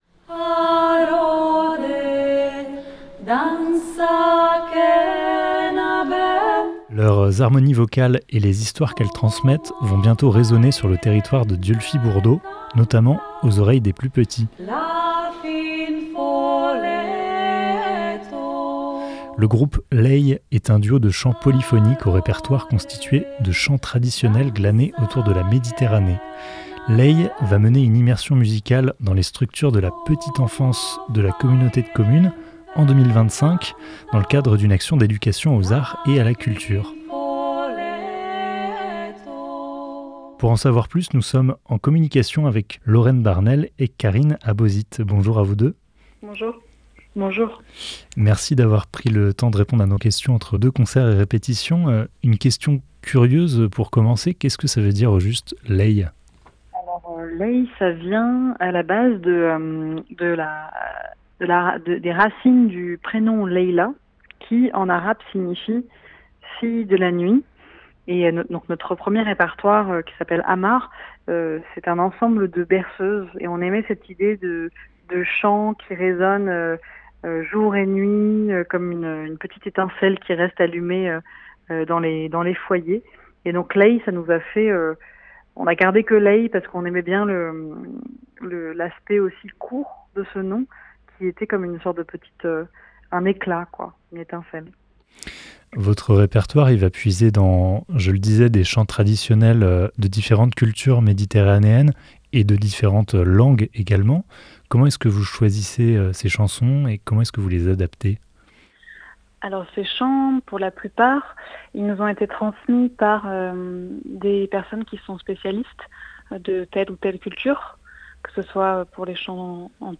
15 octobre 2024 14:46 | Interview
LEÏ est un duo de chanteuses qui s’attachent à faire vivre un répertoire de chants polyphoniques traditionnels de la Méditerranée.
Entretien téléphonique en musique